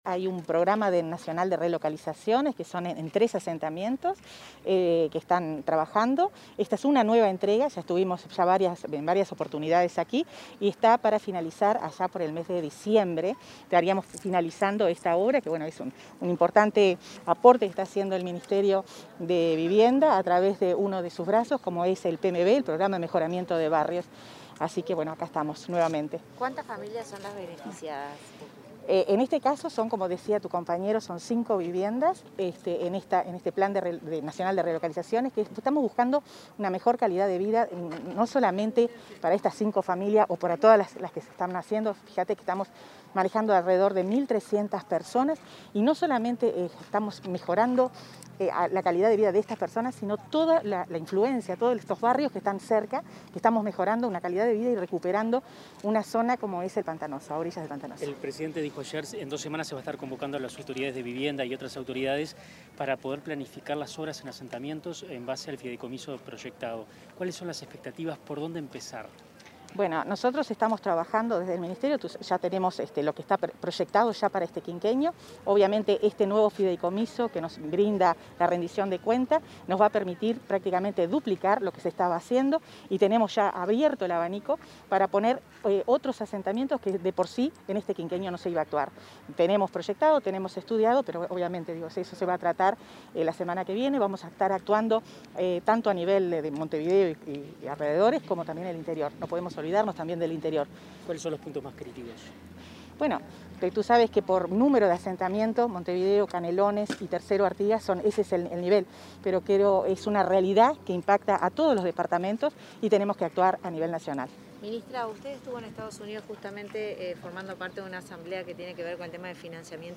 Declaraciones a la prensa de la ministra de Vivienda, Irene Moreira